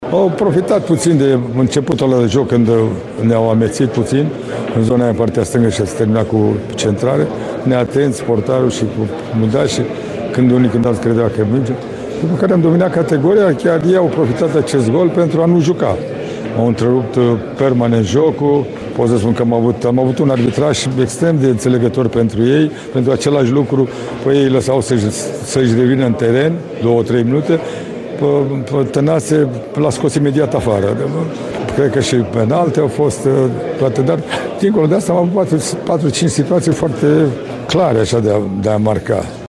Selecționerul reprezentativei noastre, Mircea Lucescu, a fost de părere că startul ezitant de meci al ”tricolorilor” și unele episode de arbitraj au contat în stabilirea acestui rezultat nedorit: